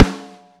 hit snare ff.wav